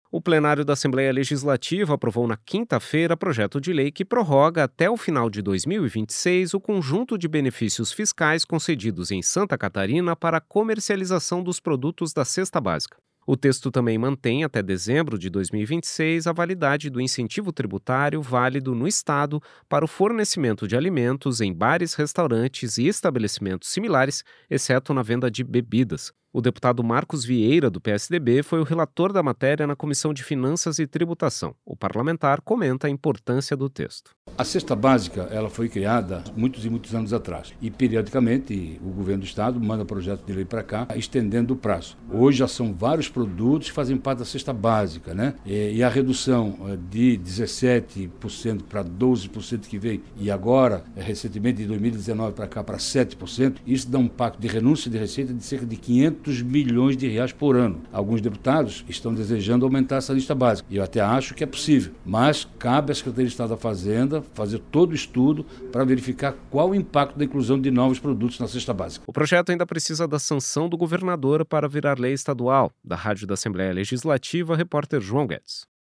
Entrevista com:
- deputado Marcos Vieira (PSDB), presidente da Comissão de Finanças e Tributação.